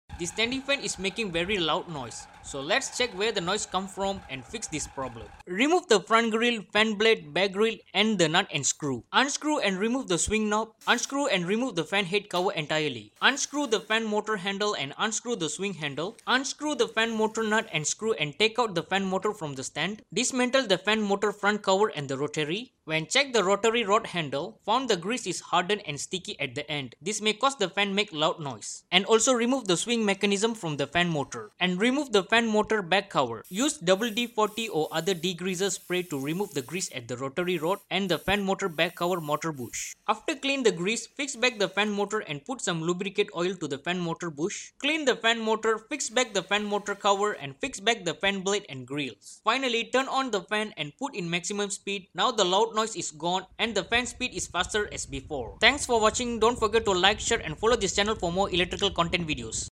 DIY Noisy Standing Fan Problem sound effects free download